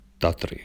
The Tatra Mountains (pronounced), Tatras (Tatry either in Slovak (pronounced [ˈtatri]
Tatry-Slovak.wav.mp3